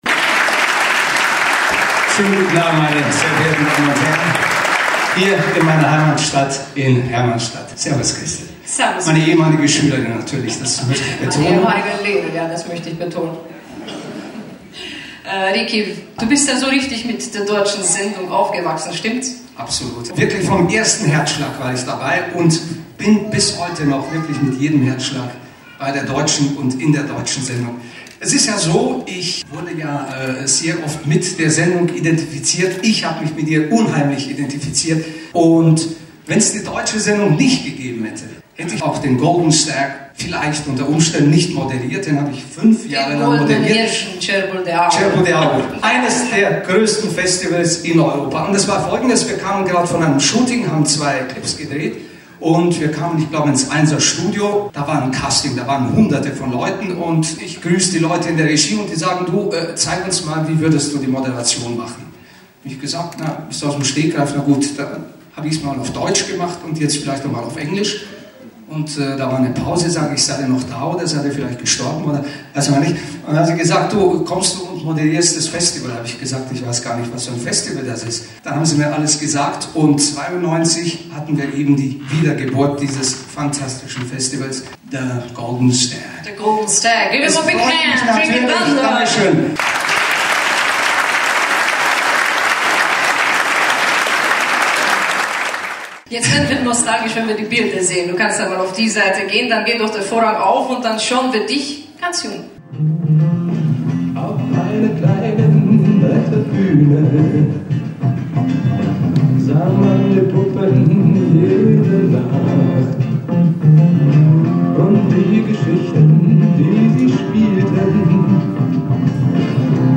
Seit nun mehr 40 Jahren gibt es sie – die deutsche Sendung im rum�nischen �ffentlich-rechtlichen Fernsehen. Ihren 40. Geburtstag feierte die deutsche Redaktion mit einer grandiosen Show am Samstag, den 23. Januar, im Thalia-Saal der Staatsfilharmonie in Hermannstadt. Rund 120 K�nstler traten auf die B�hne in einer gelungenen Mischung von Volks- und Popmusik, gepriest mit Gru�worten seitens der Ehreng�ste und Erinnerungen ehemaliger Mitgestalter der deutschen Fernsehsendung.